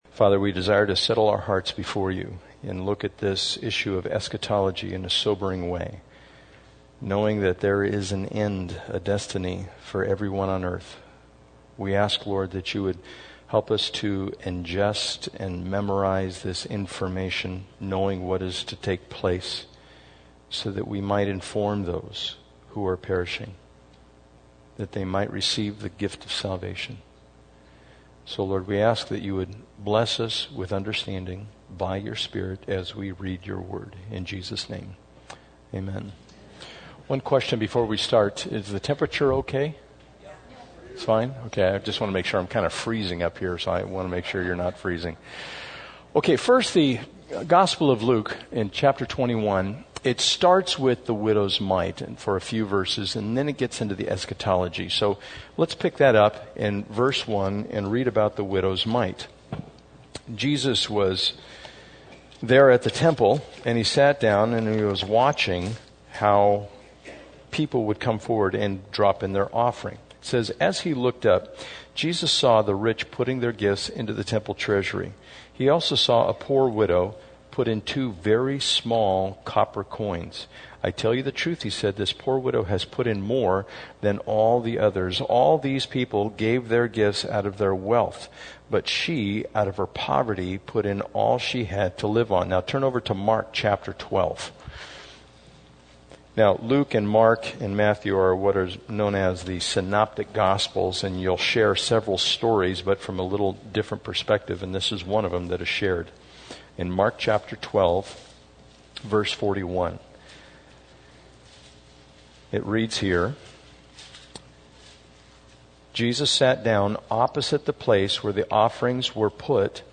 Service Type: Sunday Morning